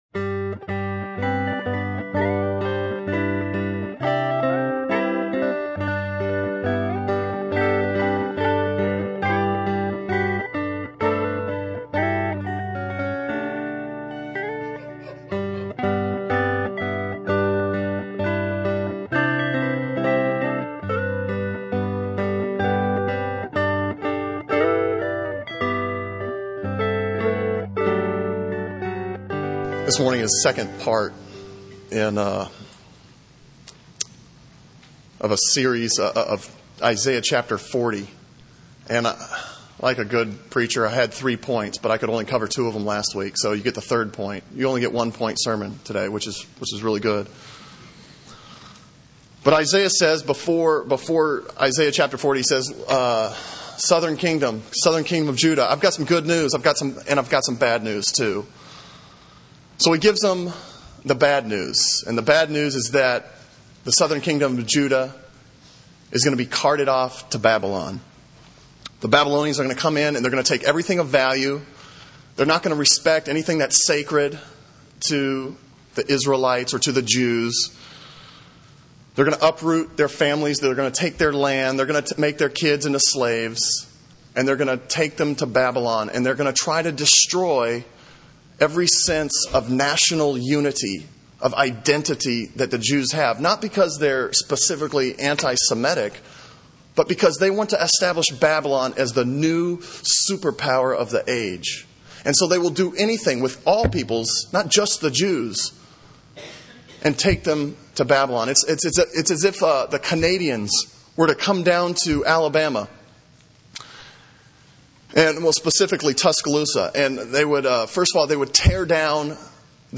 Sermon on Isaiah 40:9-31 from February 11